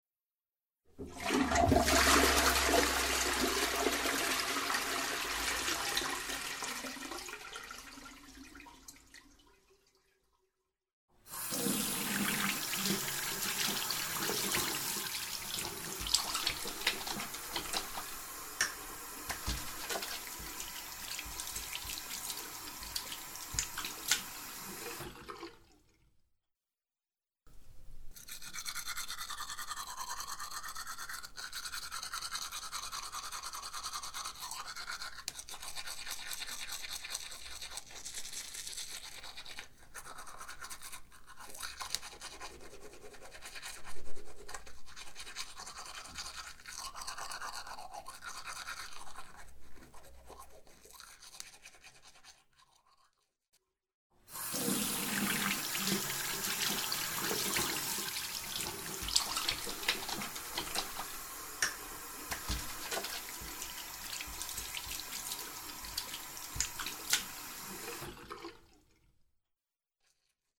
Karty aktywności - odgłosy wody - EDURANGA
ka1_s_18_2_odglosy_wody-mp3.mp3